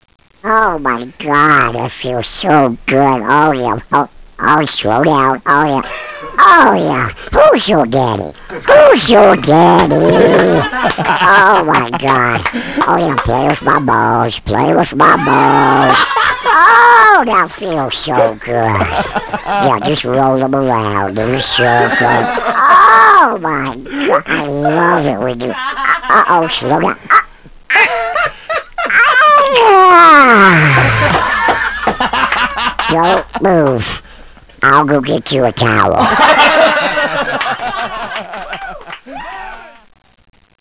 sick duck
duck.wav